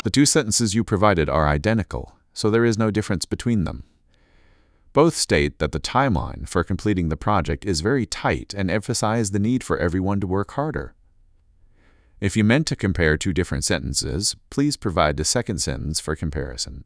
stress1_Munching_1.wav